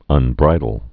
(ŭn-brīdl)